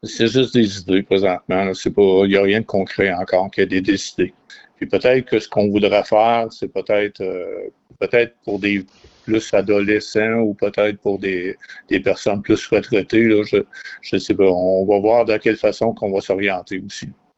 En entrevue, le maire, Jean-Luc Boisclair, a souligné que la Municipalité voudrait conserver un des 31 terrains du nouveau projet domiciliaire de la rue Leclerc pour ce projet.